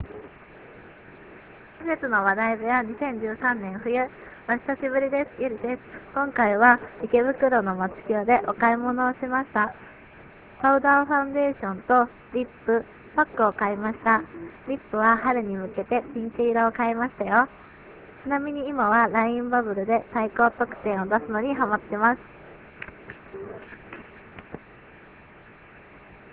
リポート等の音声はスタジオ録音ではなく現地録りになりますので他の人の声などの雑音が入っていたりしますがご了承願います。